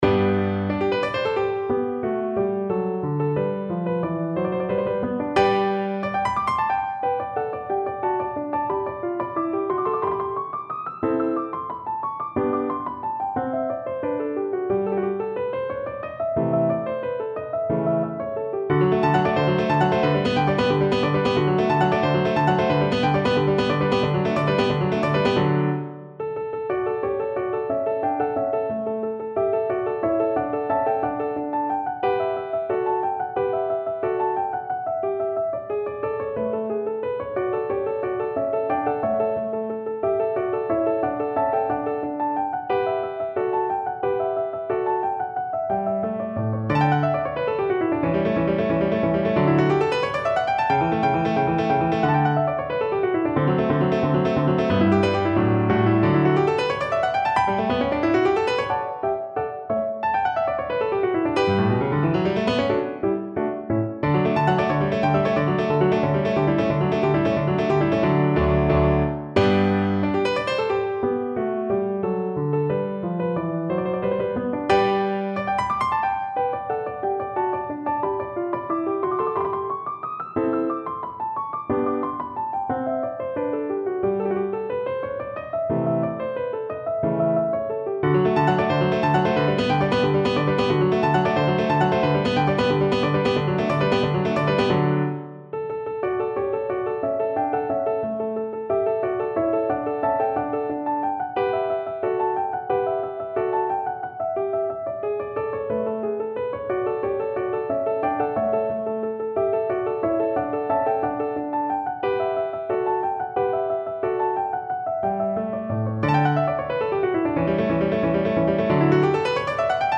No parts available for this pieces as it is for solo piano.
2/2 (View more 2/2 Music)
Piano  (View more Intermediate Piano Music)
Classical (View more Classical Piano Music)